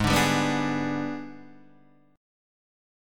G#mM7b5 chord {4 5 5 4 3 4} chord